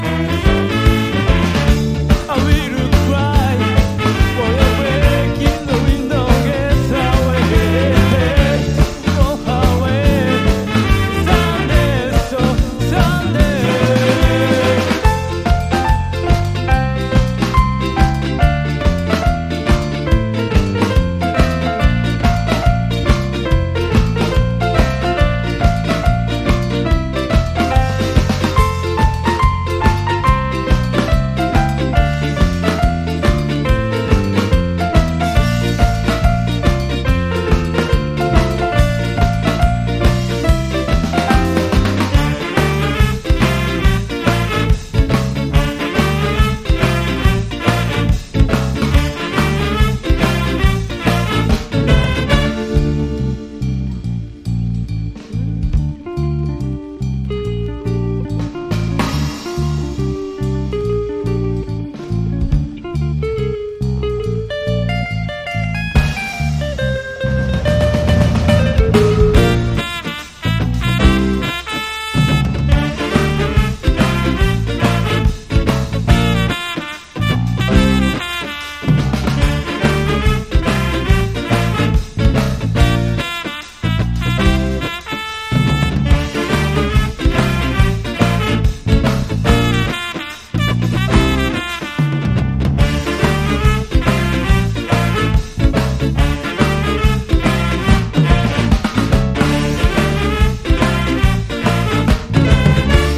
2 TONEスカ・スタイル日本代表！
見事にテリー・ホール・マナーなヴォーカルにも改めて驚かされます。”